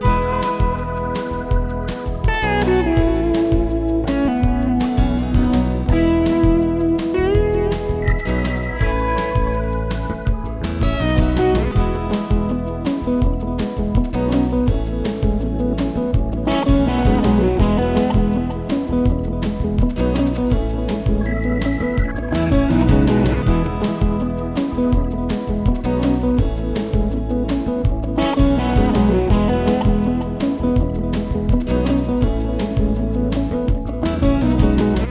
Rock sinfónico (bucle)
melodía
rítmico
rock
sinfónico